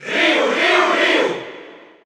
Ryu_Cheer_Spanish_SSB4_SSBU.ogg